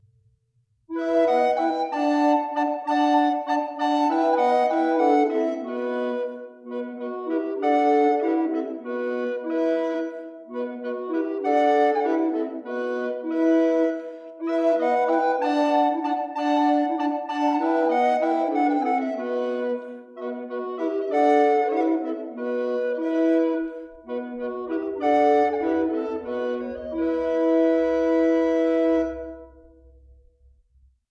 Ministriles coloniales de Guatemala
Música tradicional